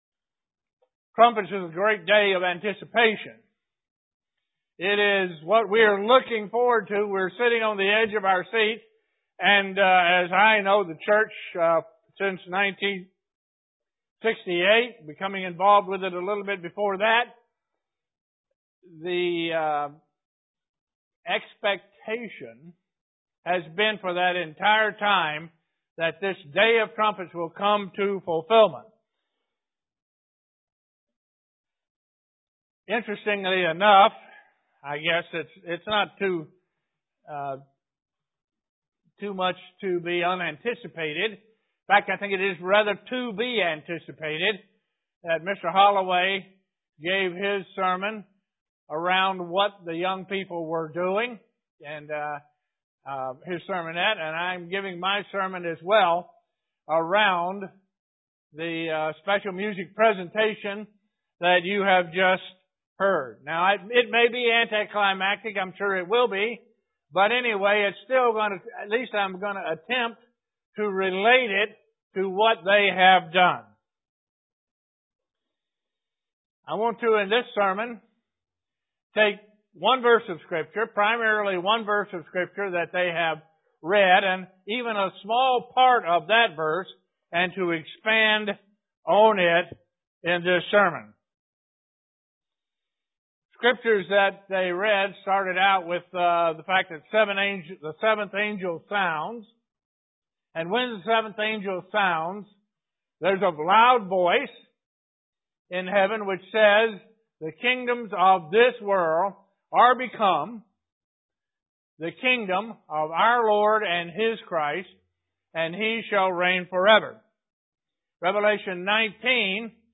Print What is the sword, the Word of God UCG Sermon Studying the bible?